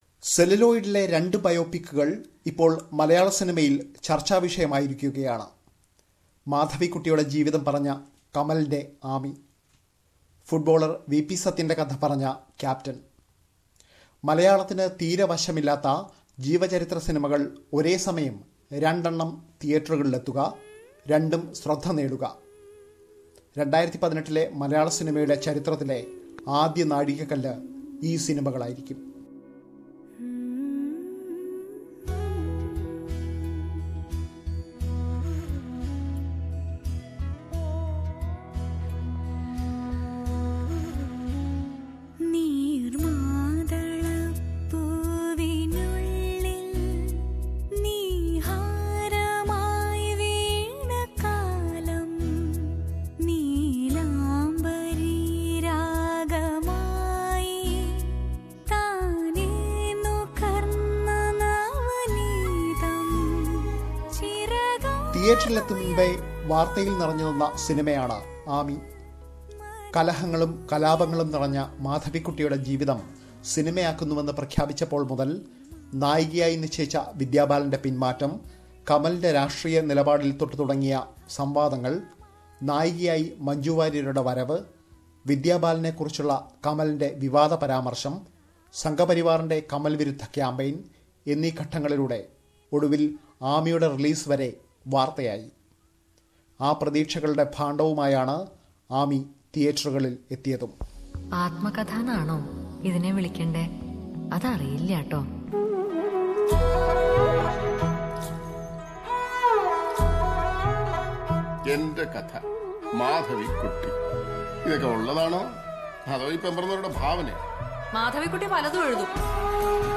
The films that tell the stories of Madhavikkutti and V.P.Sathyan have set a new tone in the Malayalam movie world. Listen to a report on what make them special...